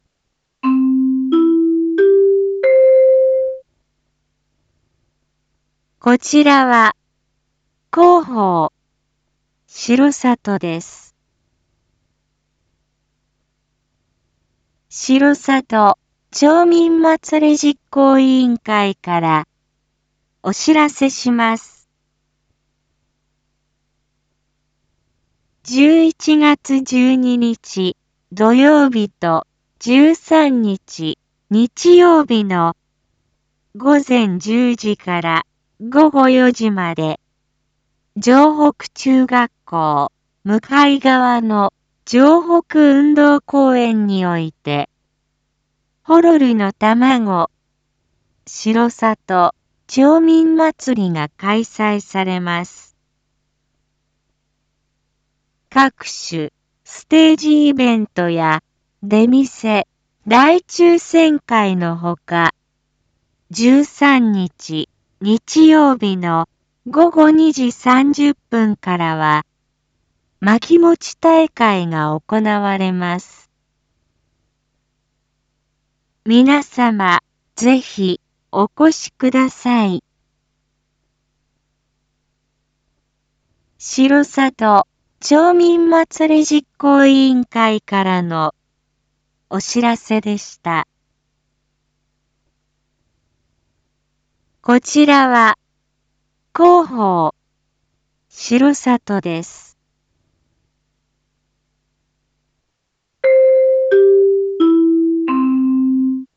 Back Home 一般放送情報 音声放送 再生 一般放送情報 登録日時：2022-11-11 19:01:48 タイトル：R4.11.11 19時放送分 インフォメーション：こちらは、広報しろさとです。